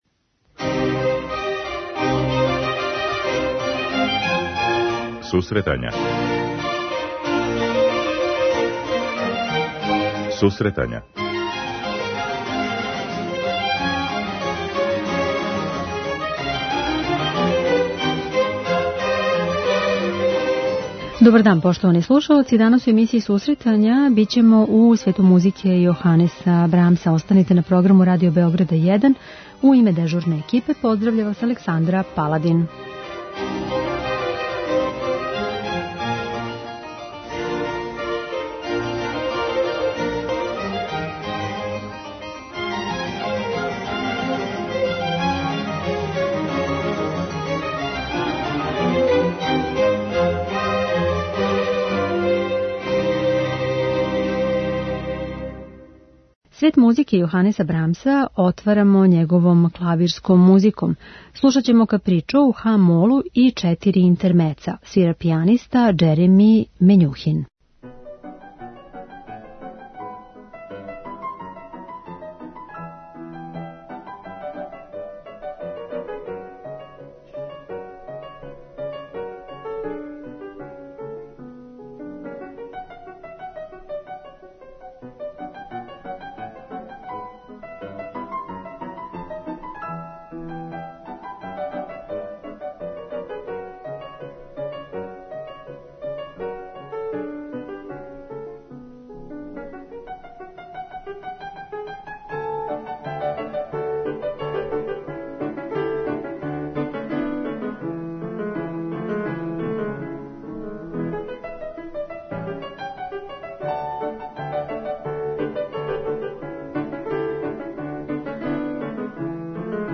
Један од великана епохе романтизма је композитор Јоханес Брамс. Представићемо га данас делима која припадају различитим жанровима - од солистичке и камерне, до концертантне и симфонијске музике.